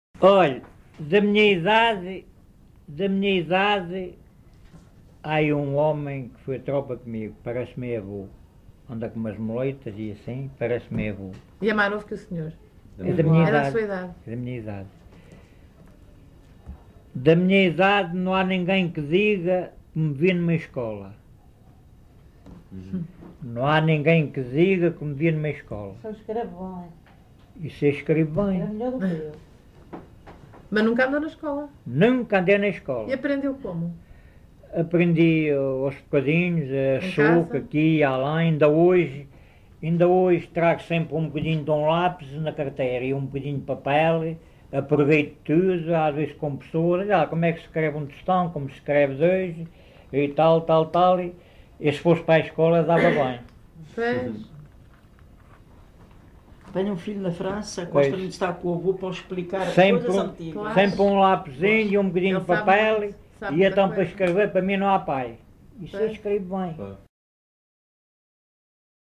LocalidadeUnhais da Serra (Covilhã, Castelo Branco)